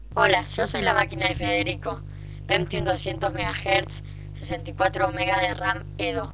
maquina.wav